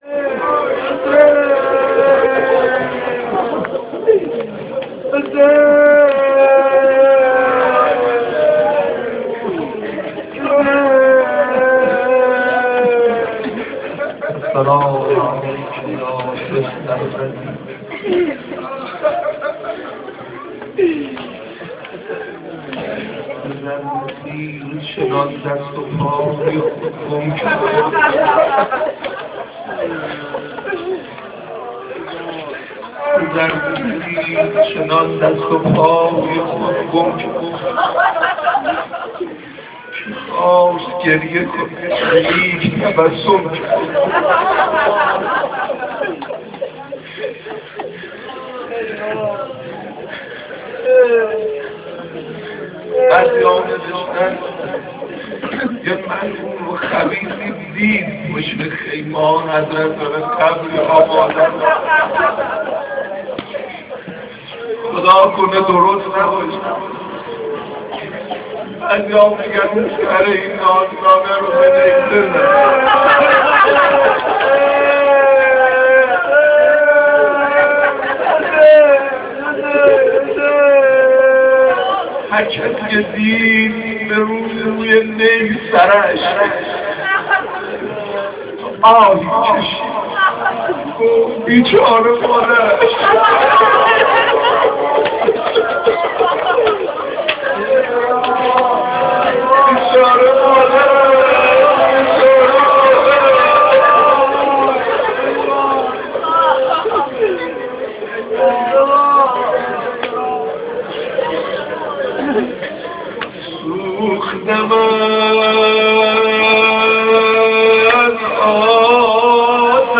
سینه زنی7.amr
سینه-زنی7.amr